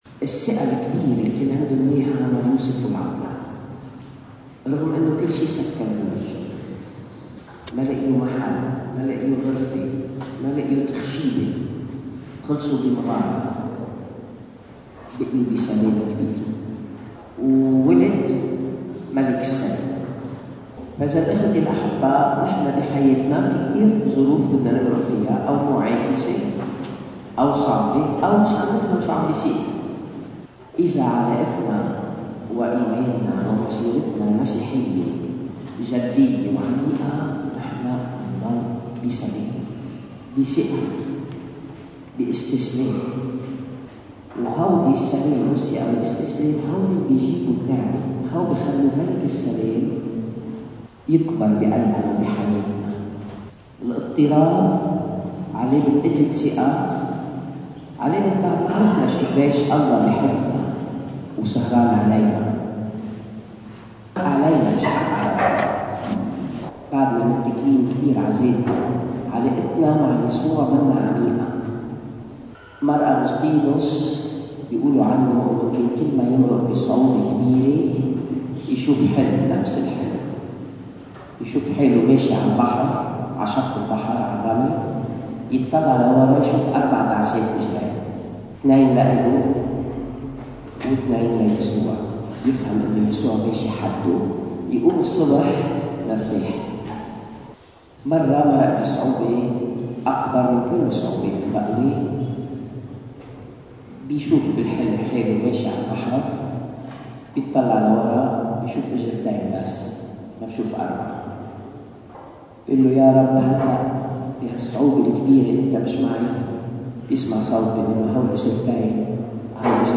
تأمل